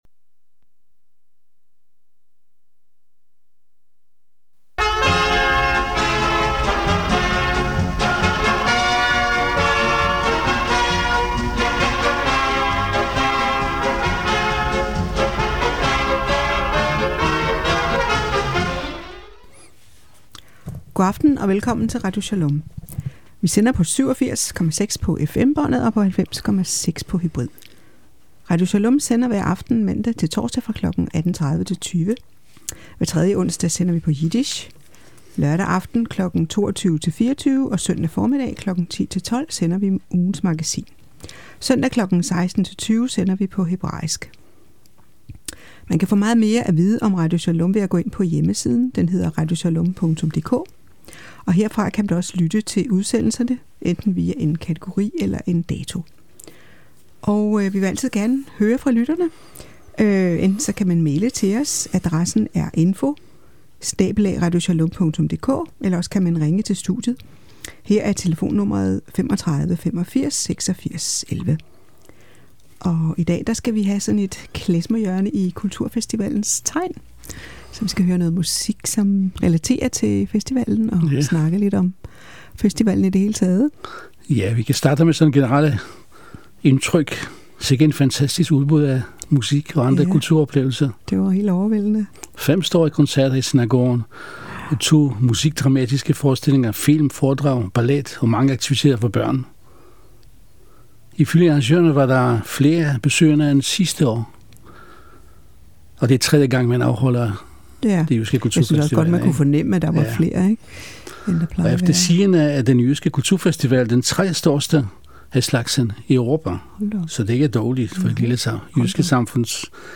Klezmer hjørne